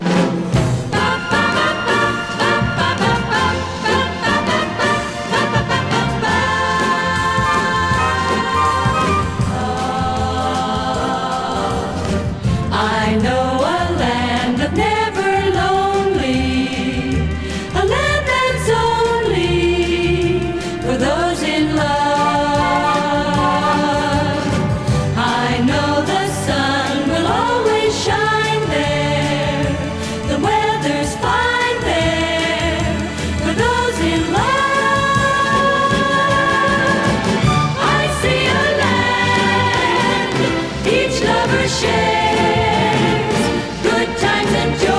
Jazz, Pop, Vocal, Easy Listening　USA　12inchレコード　33rpm　Stereo